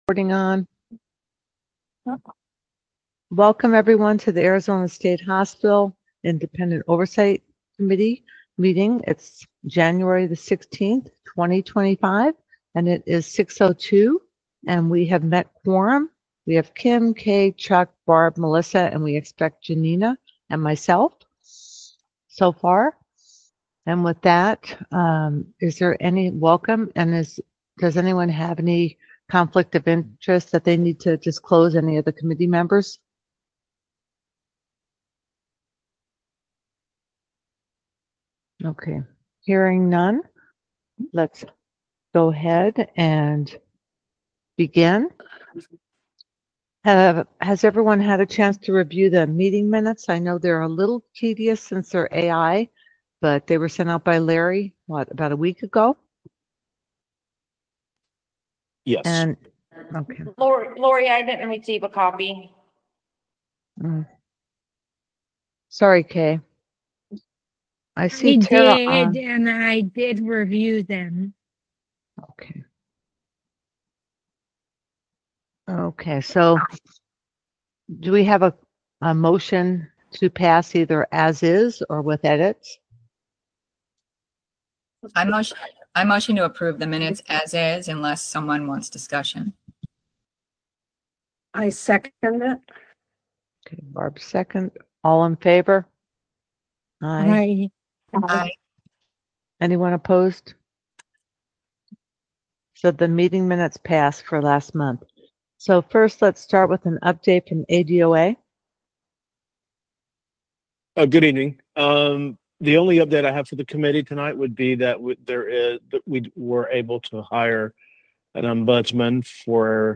Virtual Meeting Only